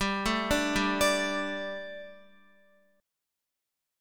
Gsus2 chord